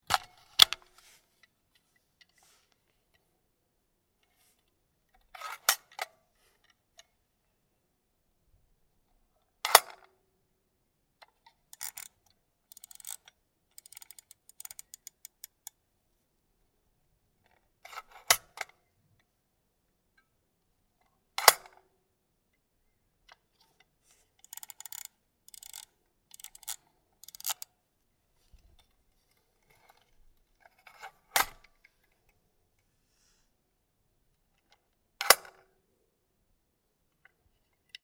Medium Format Camera - Purma Special (Beck Anastigma)